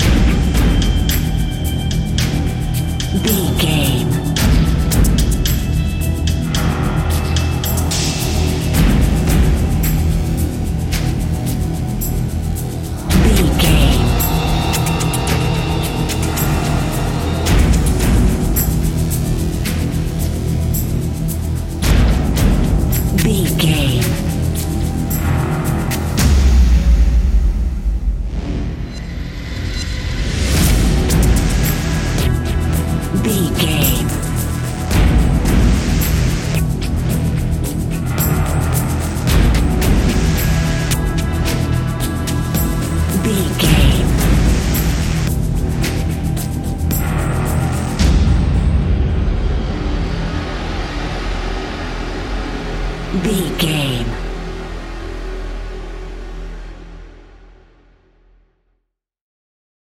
Ionian/Major
E♭
industrial
dark ambient
synths
instrumentals